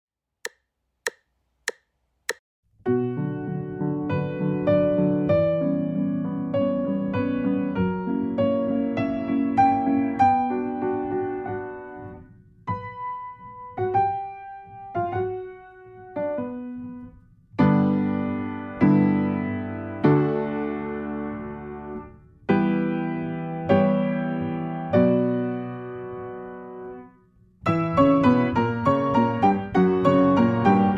akompaniamentu pianina
2 wersja: 98 bmp
Nagrania dokonane na pianinie Yamaha P2, strój 440Hz
piano